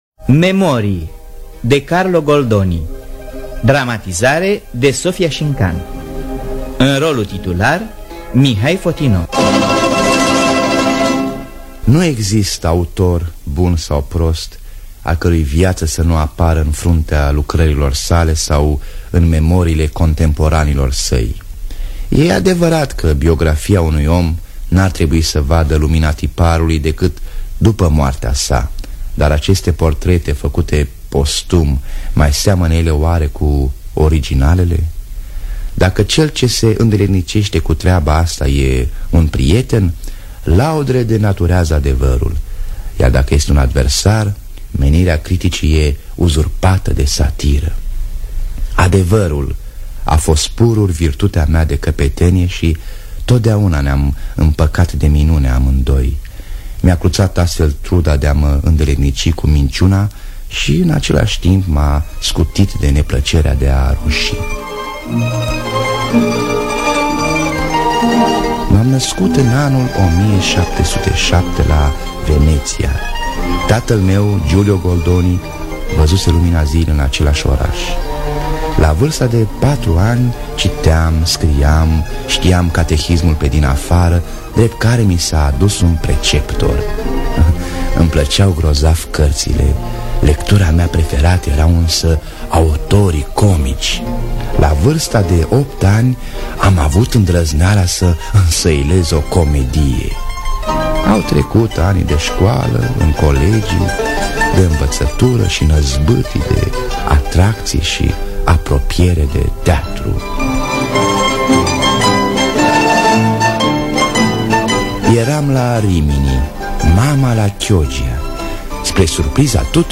Scenariu radiofonic de Sofia Șincan.